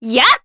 Hilda-Vox_Attack4_jp.wav